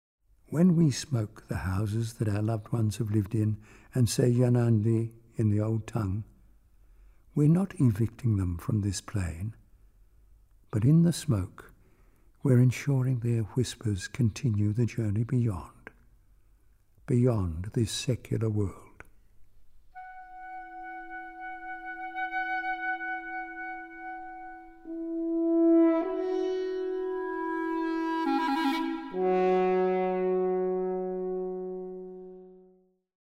Studio 420, Ferry Road, Brisbane, 6 – 8 February 2012